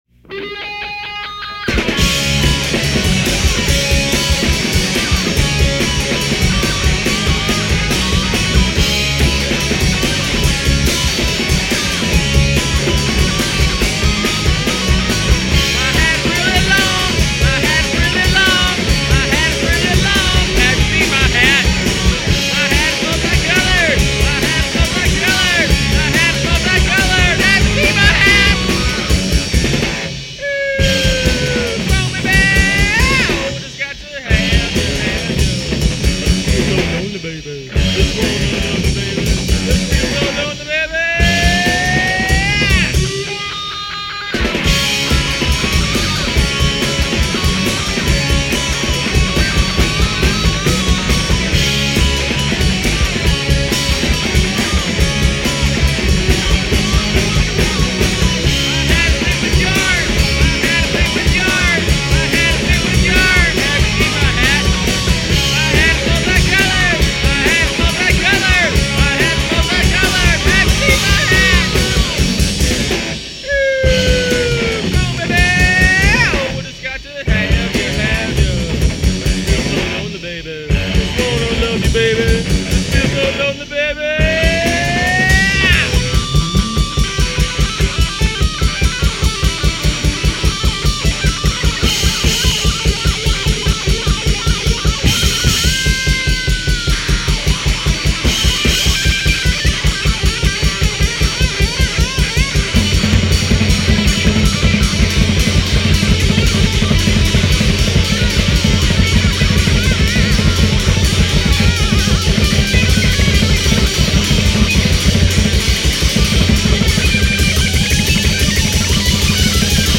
concert hall 4-track